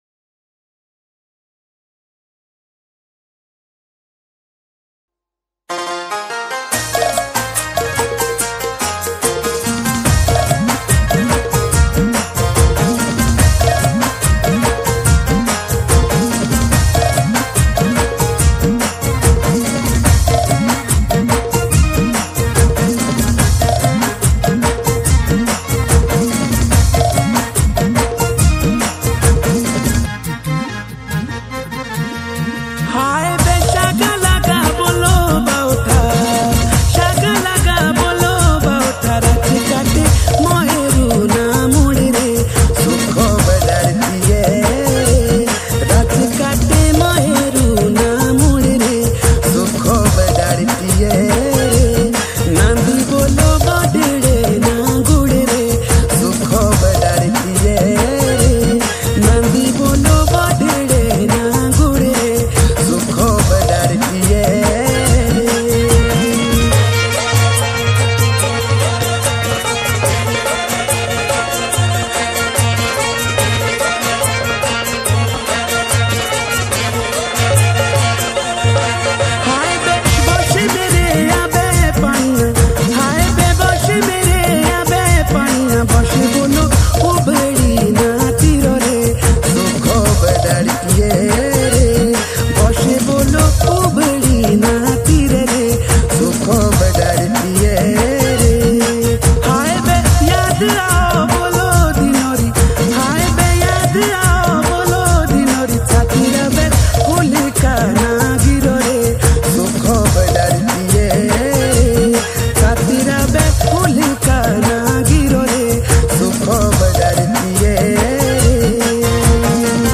himachali pahari songs